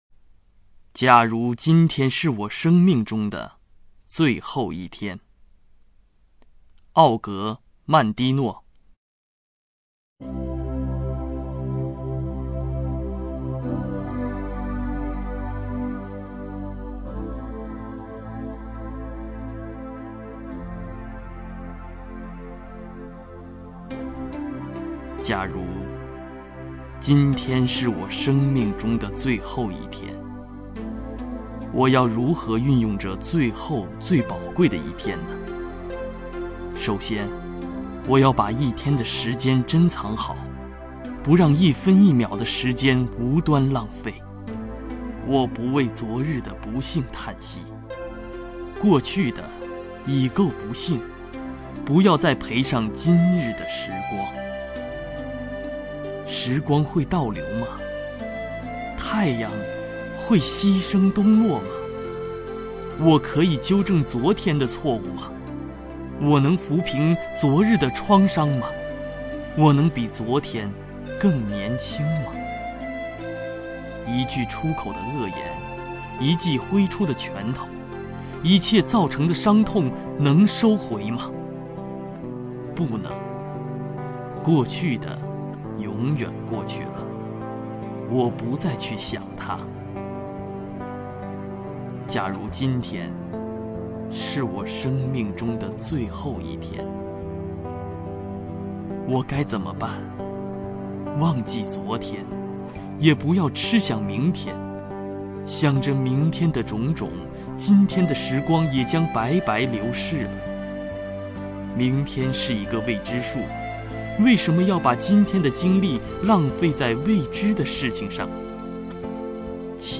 名家朗诵欣赏